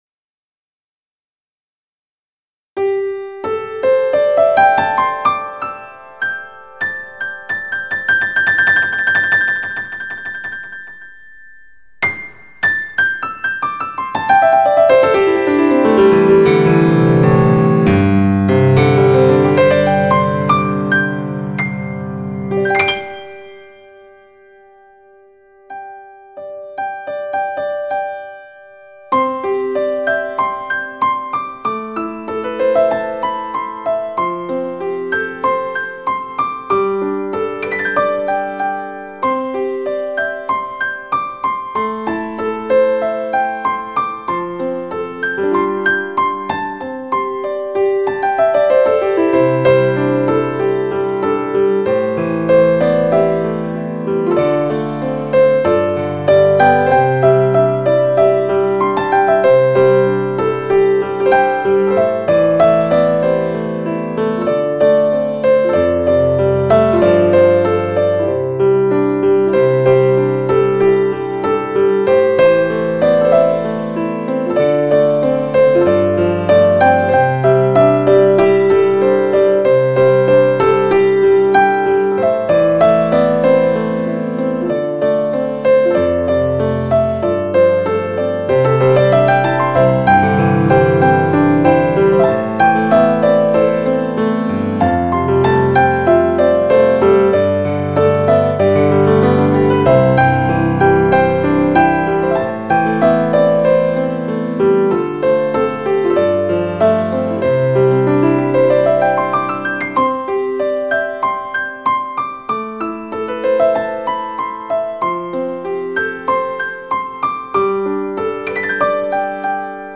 唱片类型：轻音乐